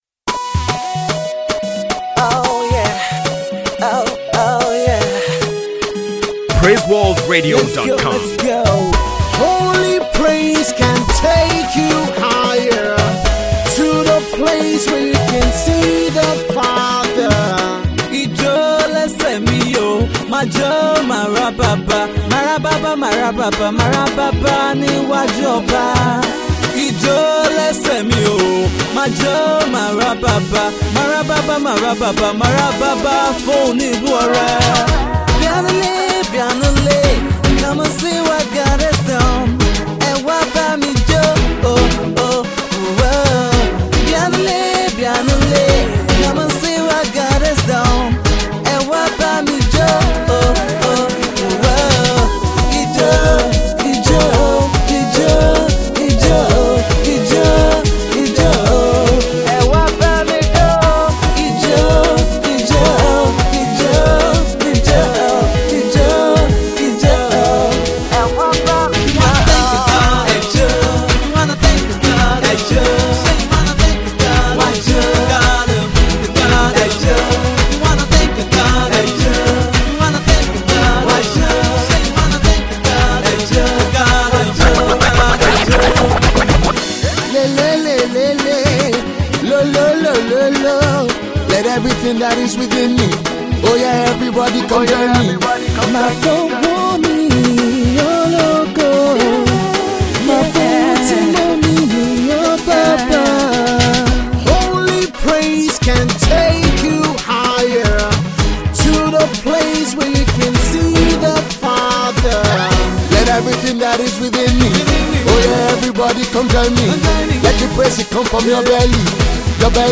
gospel group
Gospel Hip Hop